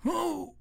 Pain_2.ogg